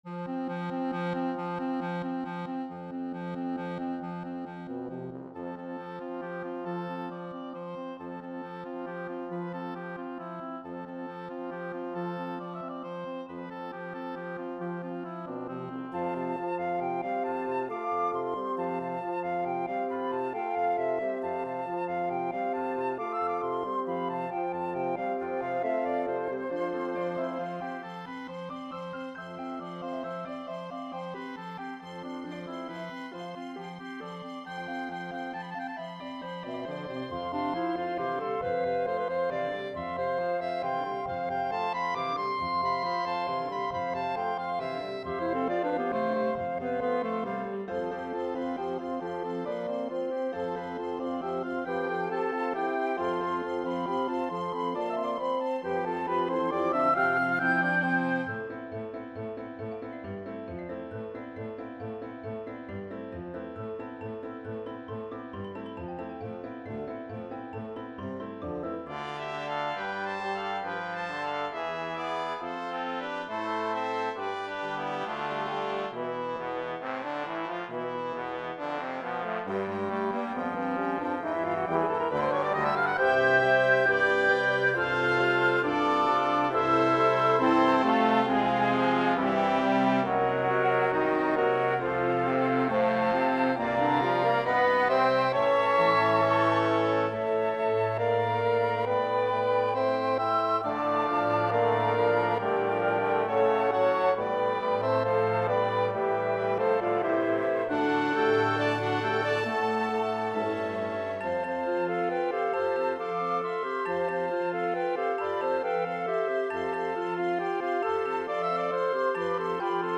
full orchestra (2010; rev. 2023)
The title derives from the fact that much of the piece is in the Lydian mode (3 whole tones + semitone + 2 whole tones + semitone).
The timpanist has to wait 182 measures before playing his first note, a D flat, and good luck with that.